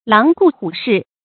狼顾虎视 láng gù hǔ shì 成语解释 如狼虎视物。形容威严而凶狠。